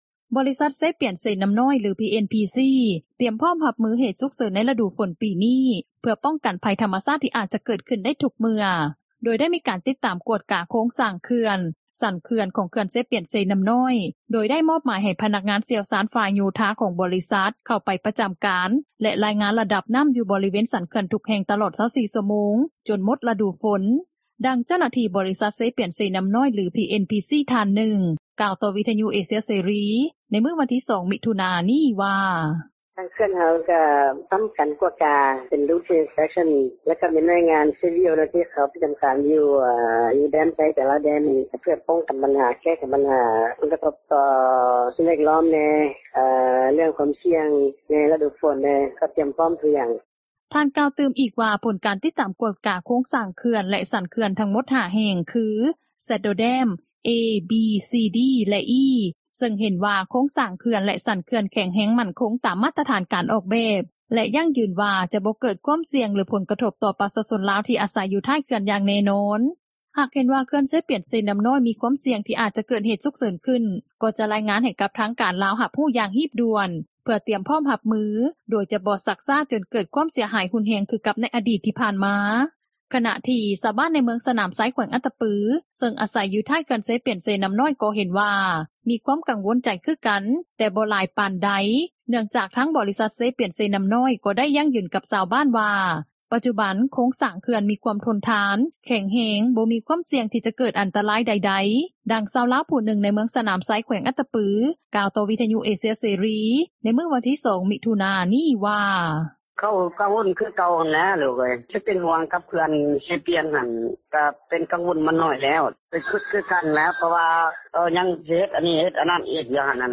ດັ່ງຊາວລາວຜູ້ນຶ່ງ ໃນເມືອງສນາມໄຊ ແຂວງອັດຕະປື ກ່າວຕໍ່ວິທຍຸເອເຊັຽເສຣີ ໃນມື້ວັນທີ່ 2 ມິຖຸນາ ນີ້ວ່າ:
ດັງຊາວບ້ານຜູ້ນຶ່ງ ໃນເມືອງສນາມໄຊ ແຂວງອັດຕະປື ກ່າວຕໍ່ວິທຍຸເອເຊັຽເສຣີ ໃນມື້ວັນທີ່ 2 ມິຖຸນາ ນີ້ວ່າ: